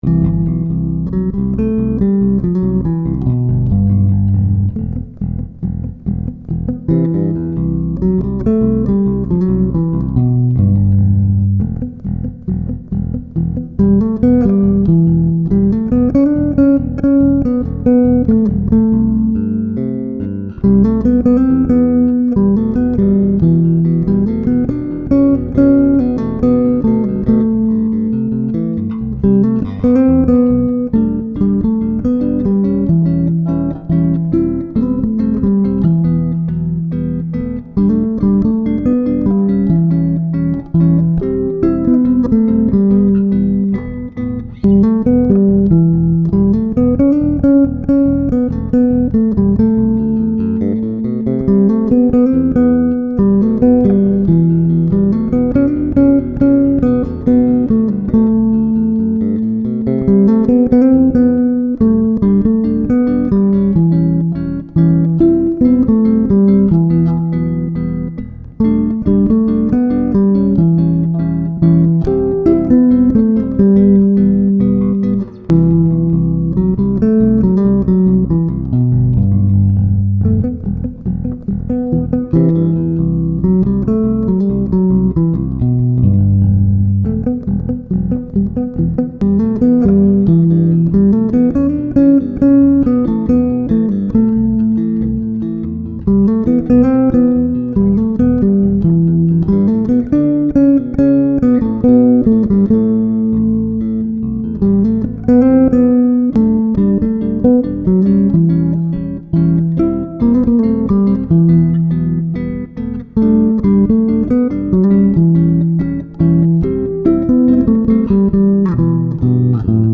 für zwei Bässe